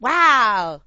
gutterball-3/Gutterball 3/Commentators/Poogie/wow.wav at 2cc26ff3afbb76918b1d87c6fbb4eb6c18932a8a
wow.wav